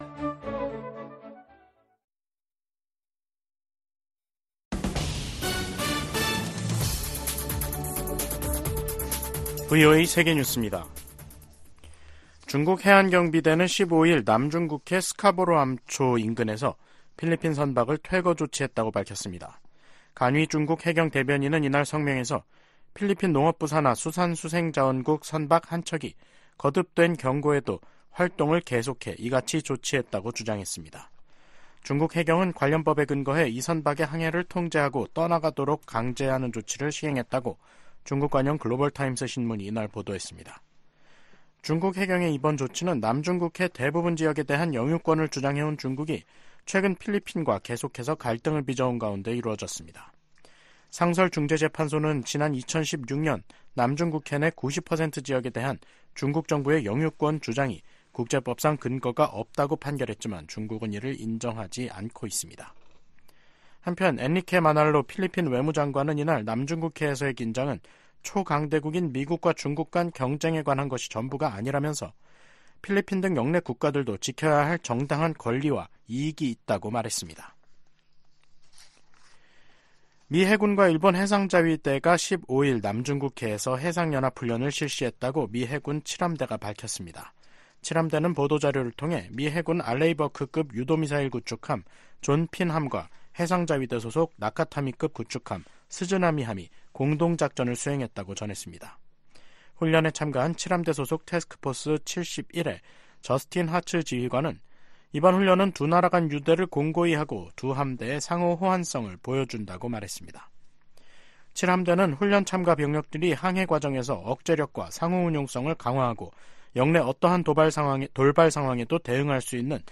VOA 한국어 간판 뉴스 프로그램 '뉴스 투데이', 2024년 2월 16일 3부 방송입니다. 미국 고위 당국자들이 북한-러시아 관계에 우려를 나타내며 국제 협력의 중요성을 강조했습니다. 김여정 북한 노동당 부부장은 일본 총리가 평양을 방문하는 날이 올 수도 있을 것이라고 말했습니다.